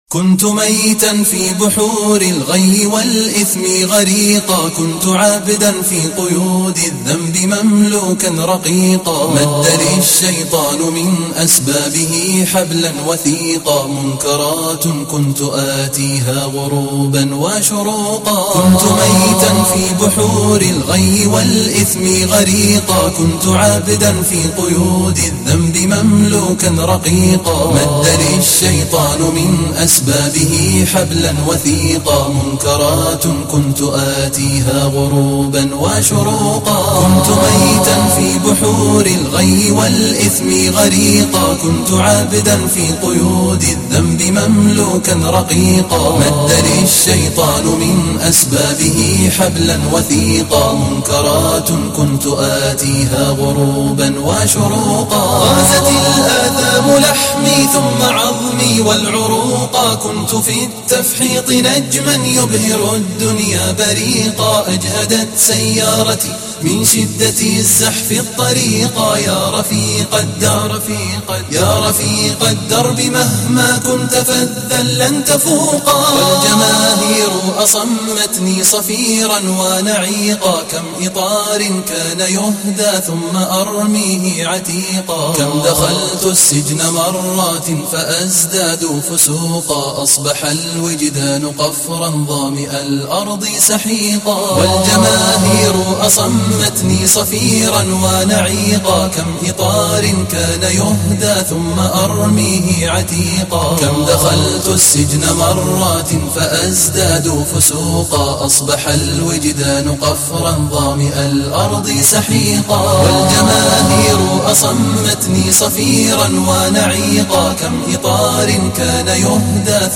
nashid_kuntu_maytan.mp3